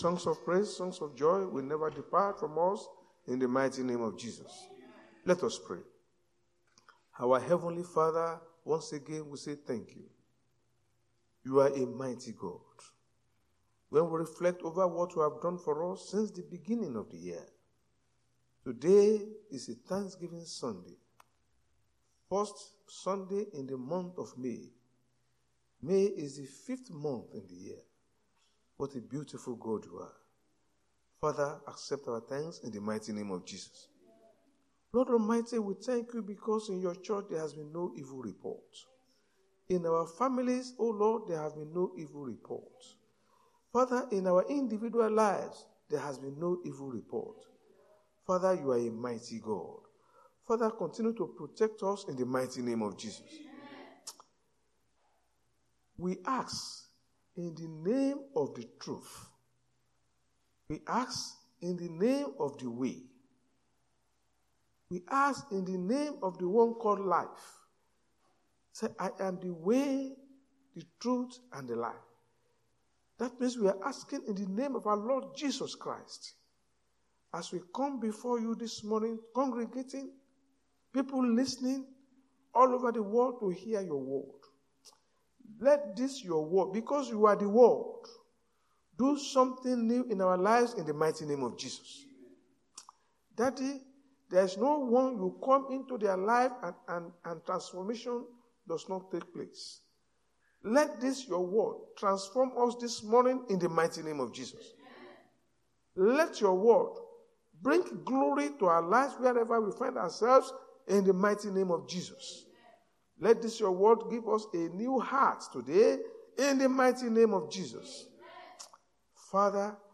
RCCG-House Of Glory's Sunday Sermons.
Service Type: Sunday Church Service